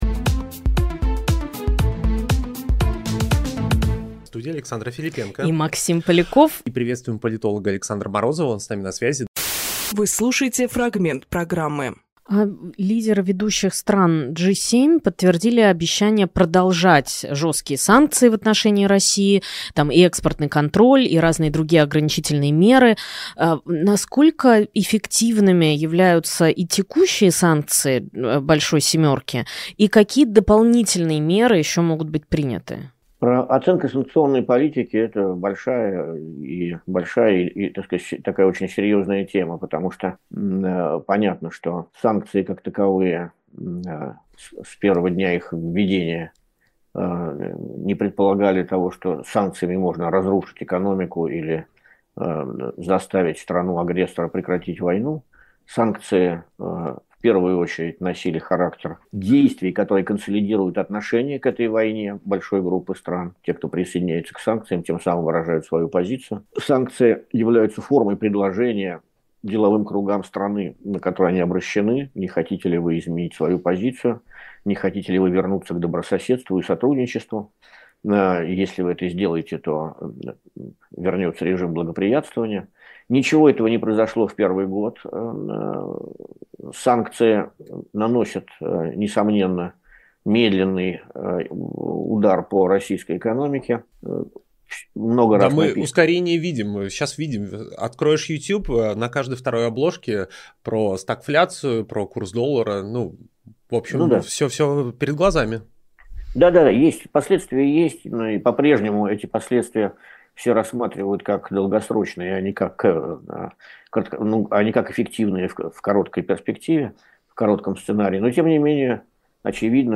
Фрагмент эфира от 17.11.24